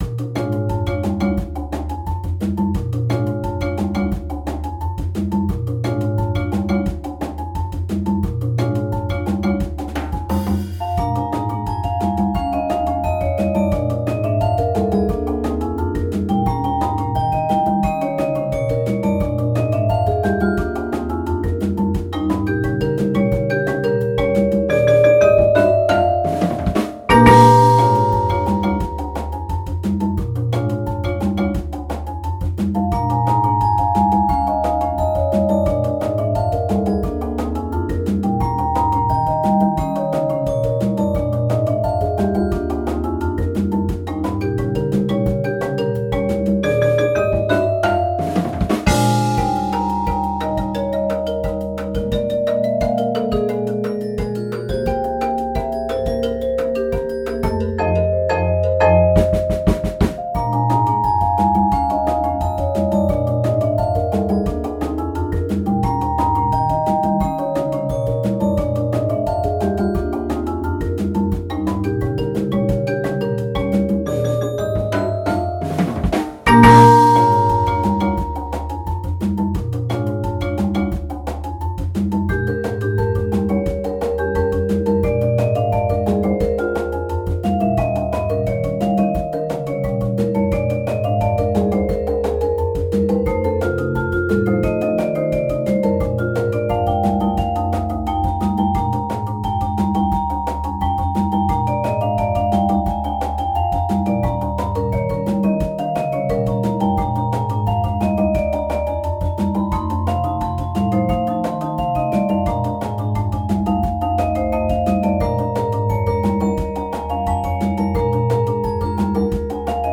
Genre: Solo Vibraphone with Percussion Ensemble
Solo Vibraphone
Bells
Xylophone
Marimba 1 (4-octave)
Marimba 2 (5-octave)
Drum Set
Auxiliary Percussion (Congas, Cowbell)